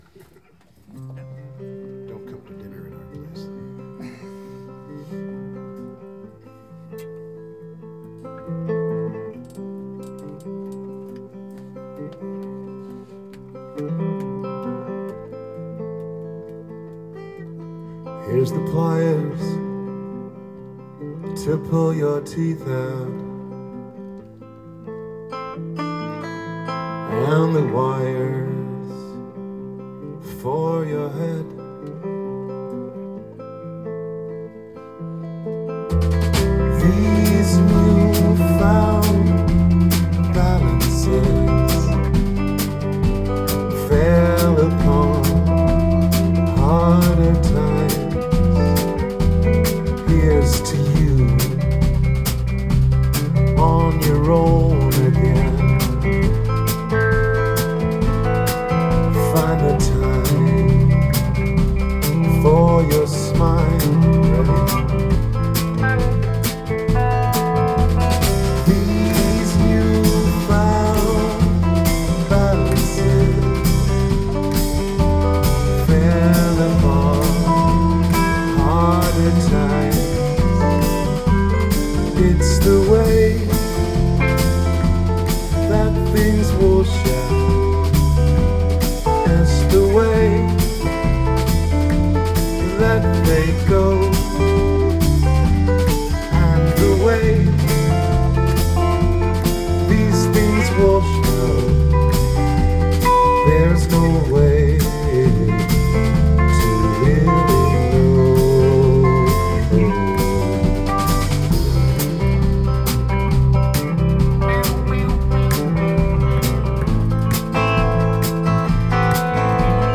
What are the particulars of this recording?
Rehearsals 16.8.2013